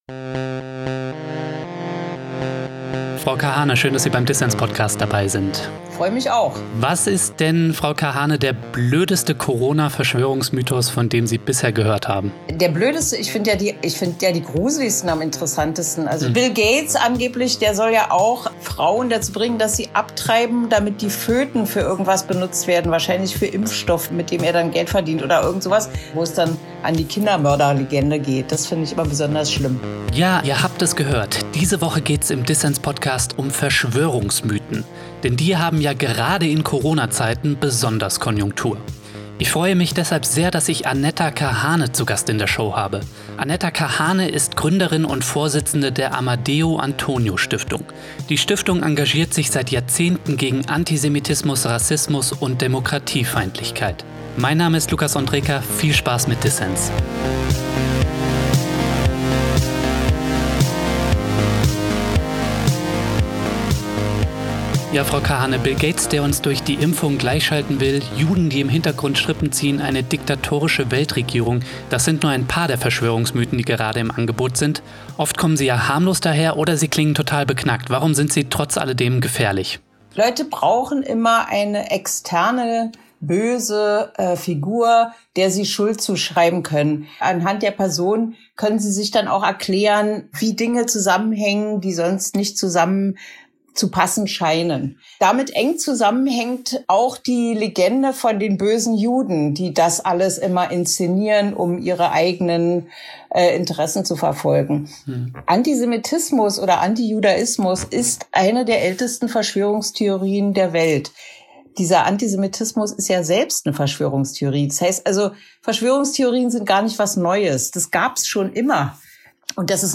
Politik und Gesellschaft müssen jetzt Haltung zeigen gegen die Corona-Querfront und ihr antisemitisches Fundament. Ein Gespräch über Verschwörungsmythen, Lehren aus Pegida und Männer-Flausen.